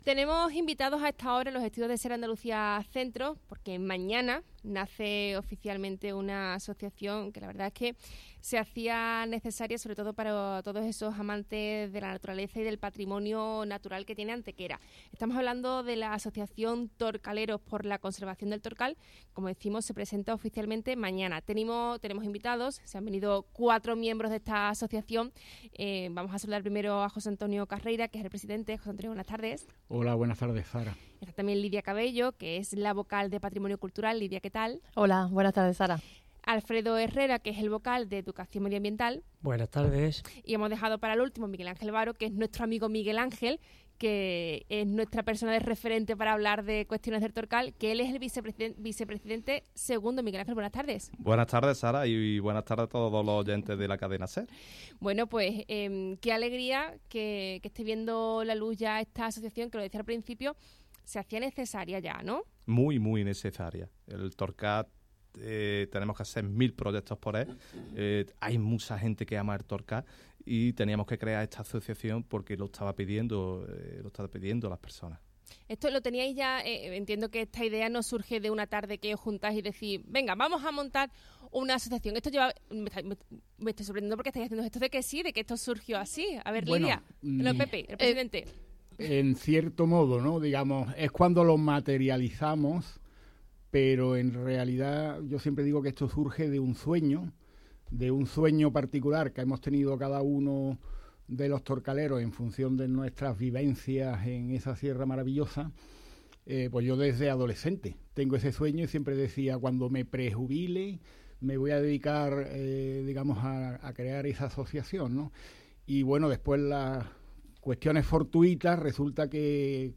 Entrevista Asociación Torcaleros por la conservación del Torcal.